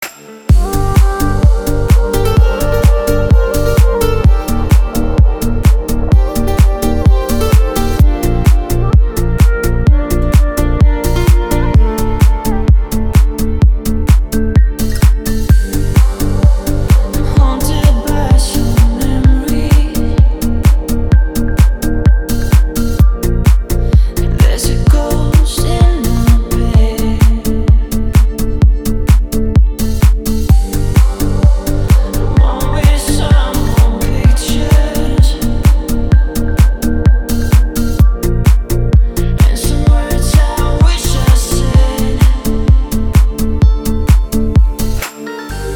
• Качество: 320, Stereo
поп
гитара
ритмичные
deep house
dance
Electronic
красивый женский голос